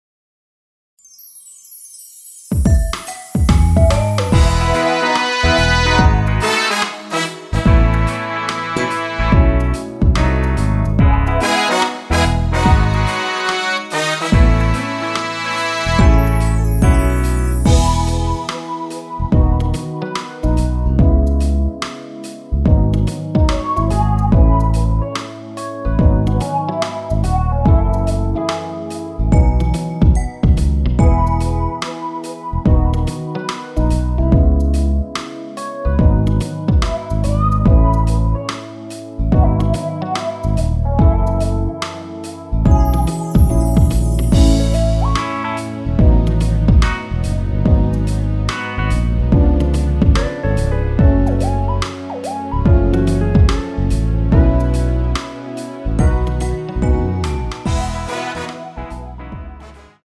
원키에서(-1)내린 MR입니다.
앞부분30초, 뒷부분30초씩 편집해서 올려 드리고 있습니다.
곡명 옆 (-1)은 반음 내림, (+1)은 반음 올림 입니다.